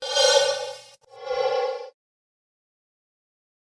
Free SFX sound effect: Gas Mask Hood.
Gas Mask Hood
yt_CbKBgUqMRWo_gas_mask_hood.mp3